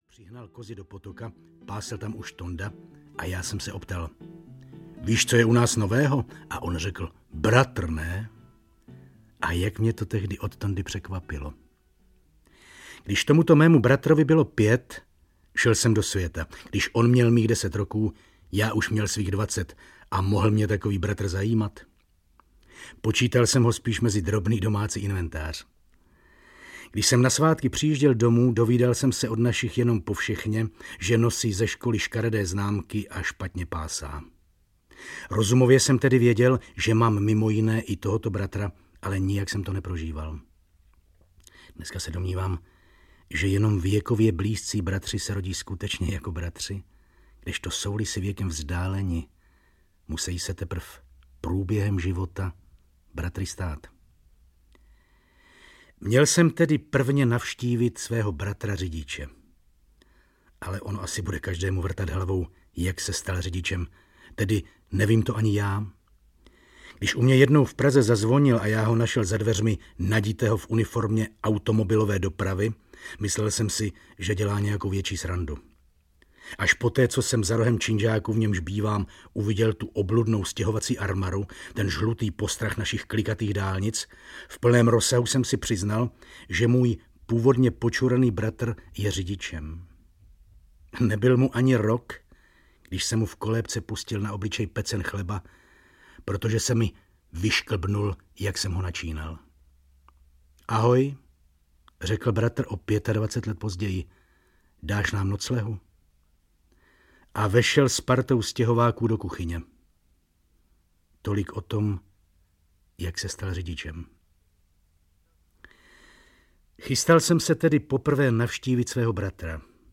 Audio kniha
Ukázka z knihy
• InterpretJan Kačer